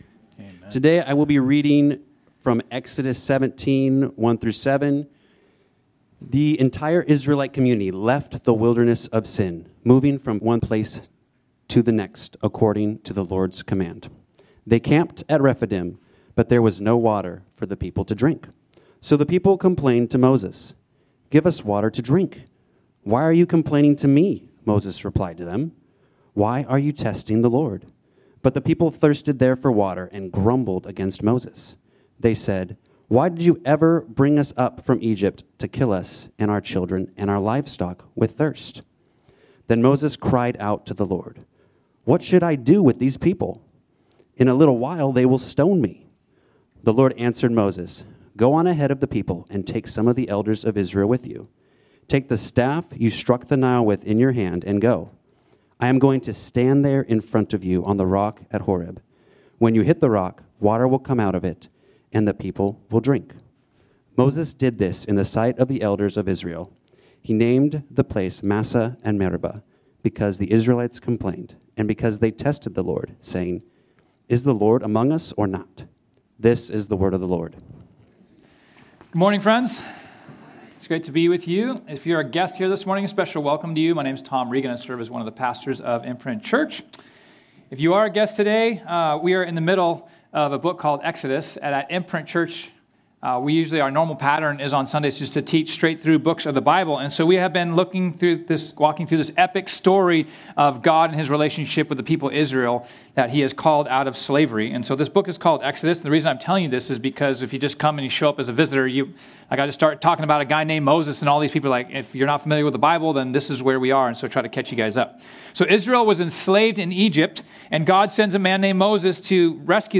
This sermon was originally preached on Sunday, March 2, 2025.